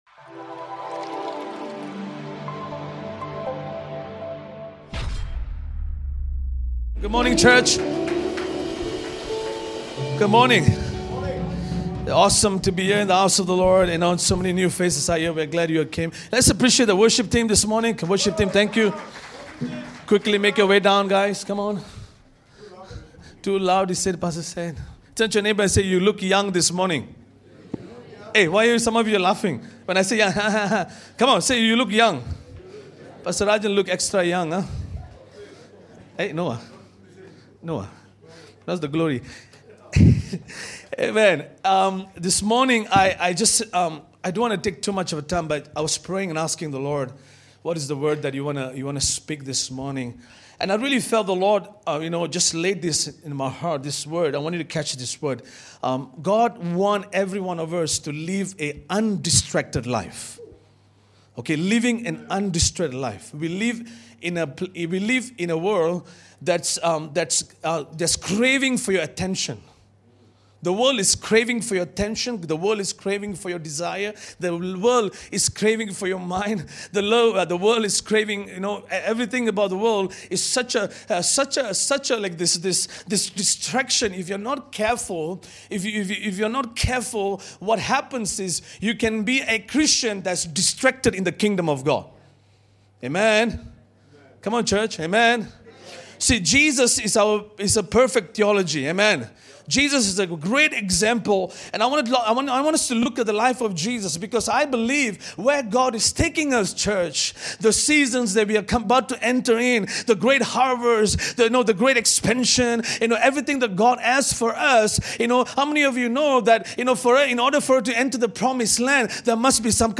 Audio Sermon | Calvary Community Church Johor Bahru